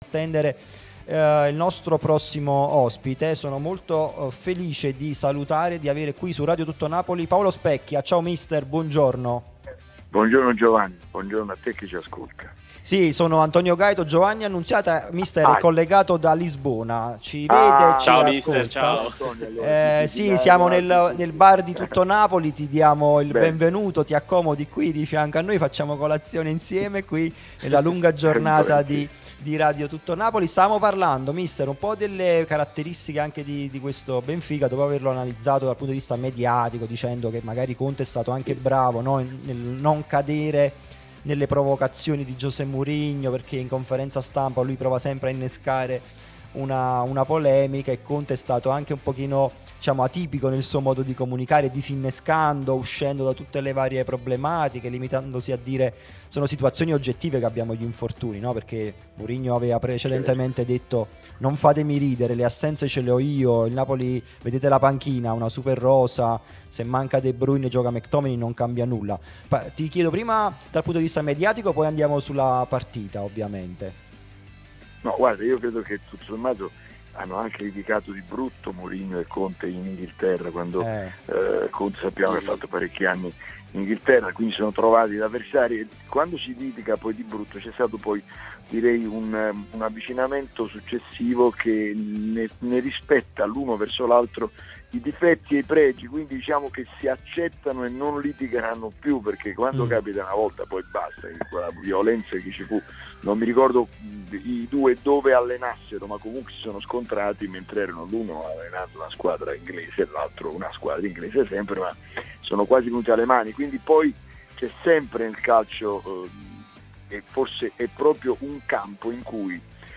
prima radio tematica sul Napoli, in onda tutto il giorno